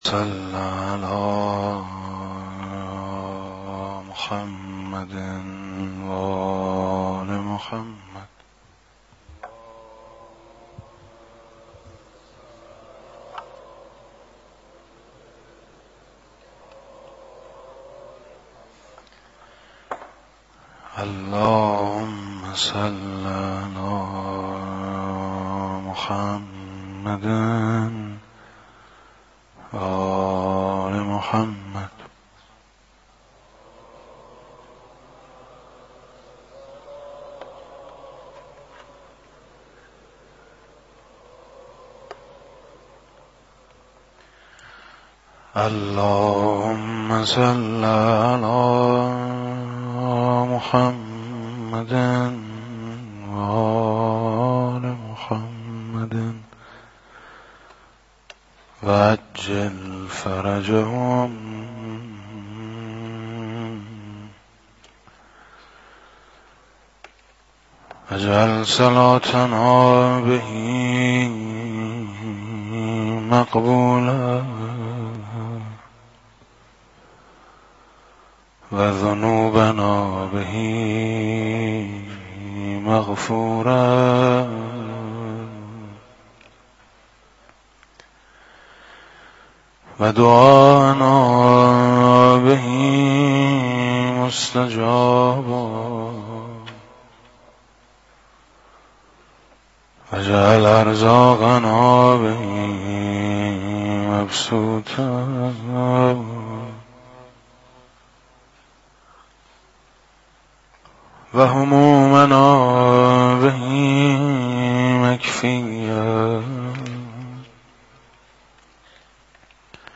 مداحی جدید حاج میثم مطیعی شب 7 رمضان 96
shabe_7_ramezan_96_motiee.mp3